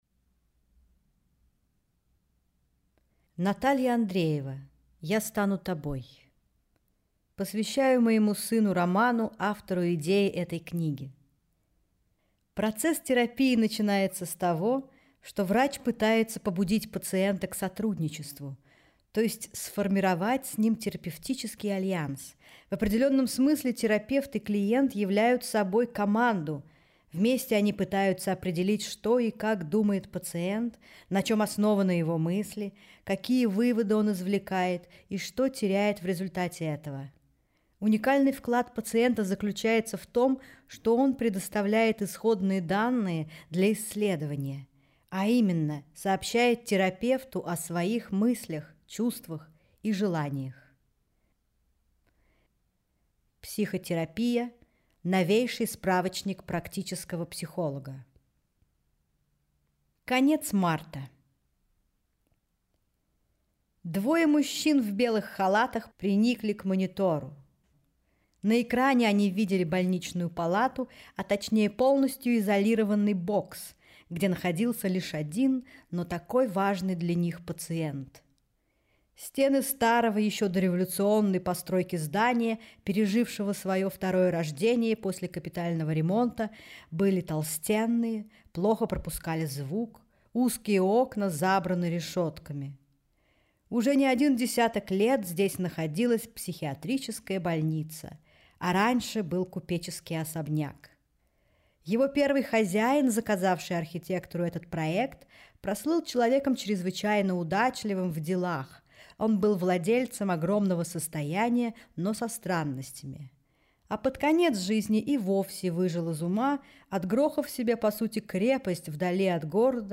Аудиокнига Я стану тобой | Библиотека аудиокниг
Прослушать и бесплатно скачать фрагмент аудиокниги